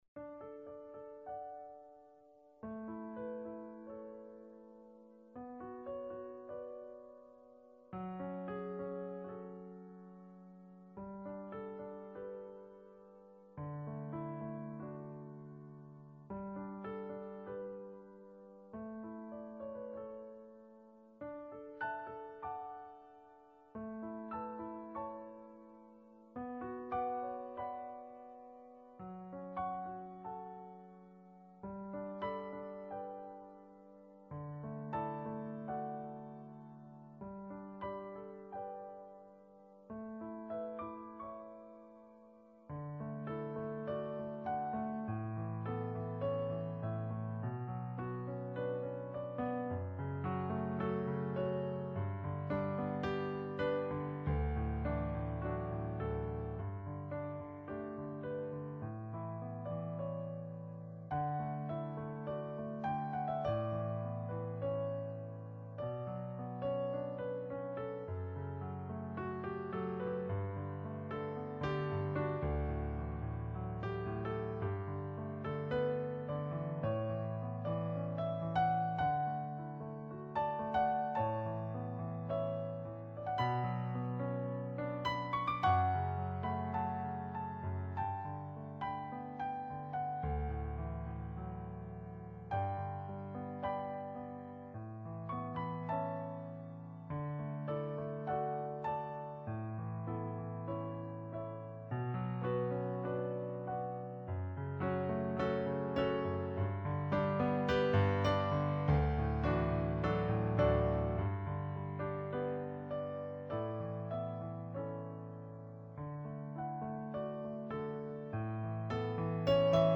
Canon_in_D_Piano.mp3